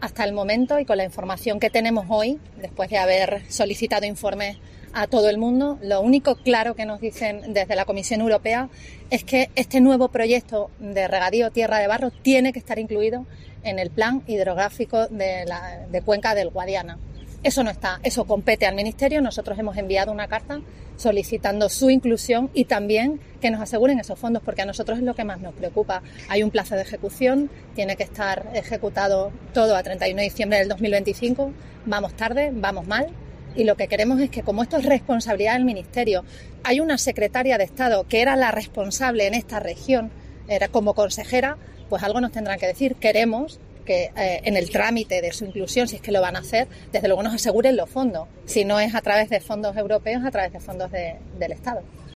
COPE le ha preguntado a la presidenta de la Junta, María Guardiola, que reconoce que “vamos tarde y mal” y pide que se aseguren los fondos: si no es a través de la Unión Europea, que sea través del Estado.